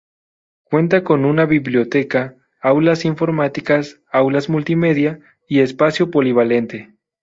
po‧li‧va‧len‧te
/polibaˈlente/